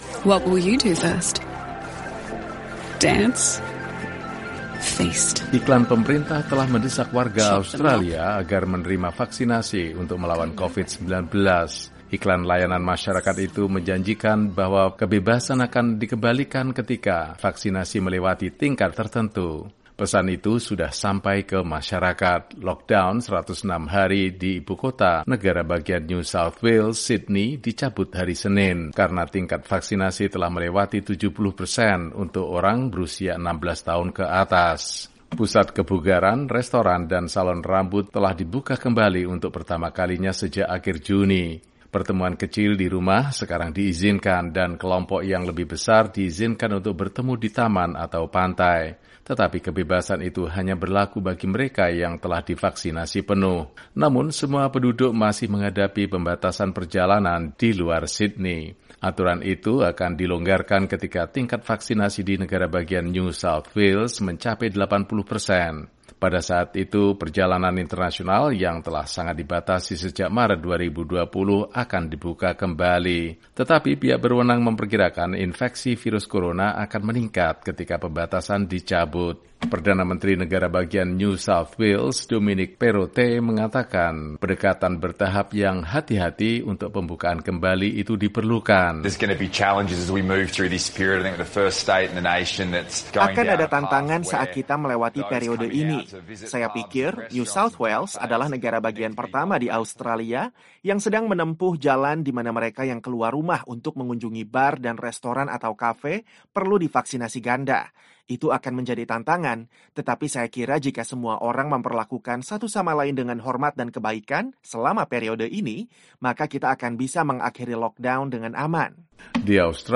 Lockdown atau pembatasan sosial berskala besar terkait COVID-19 di kota terbesar Australia, Sydney, berakhir Senin (11/10) bagi penduduk yang telah divaksinasi. Koresponden VOA di Sydney melaporkan bahwa perintah tinggal di rumah yang diberlakukan pada 26 Juni telah dicabut.